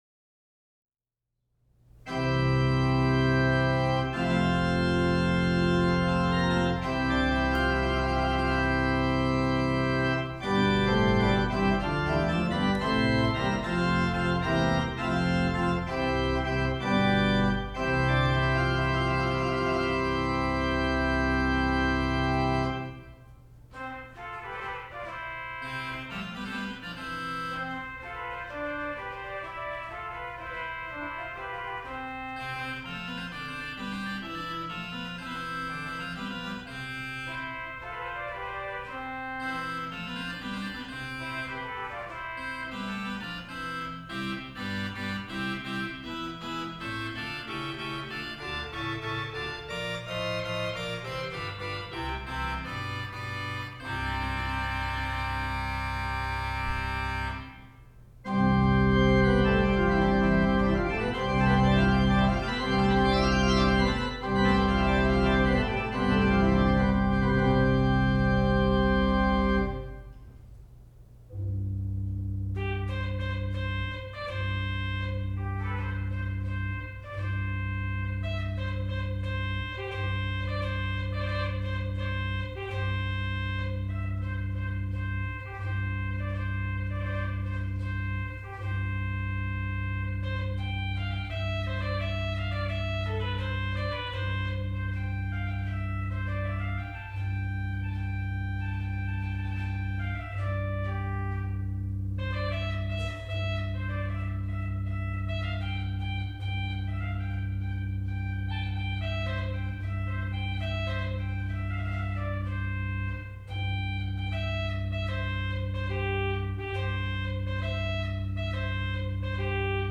The Richard Howell organ at Saint John's Episcopal Church, Lynchburg, Virginia
3 manuals and pedal,32 stops (2,063 pipes)
Played in recital